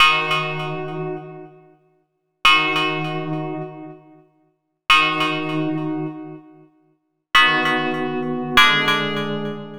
Rock Star - Bell Keys.wav